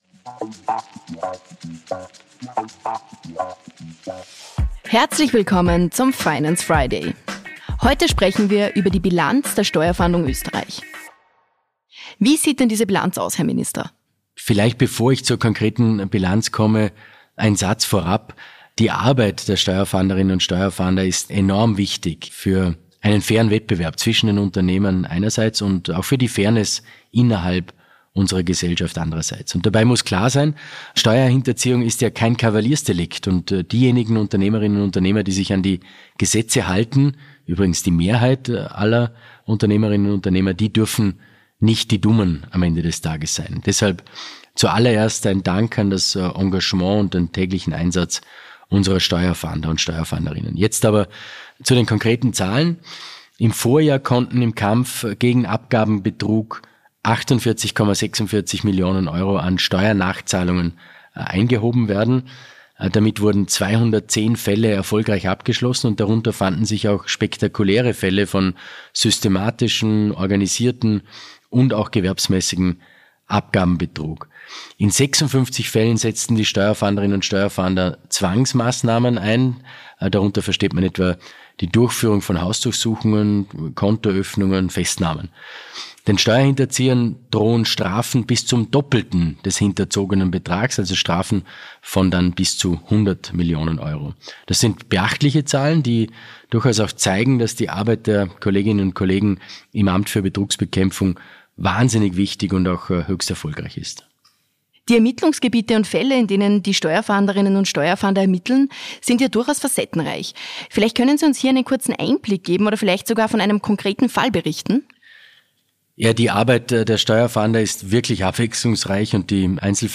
Steuerfahnders aussieht, erklären Finanzminister Magnus Brunner und